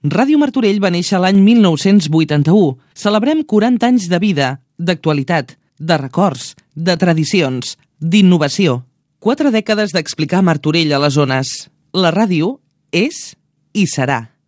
Enregistrament amb motiu del Dia Mundial de la Ràdio 2021.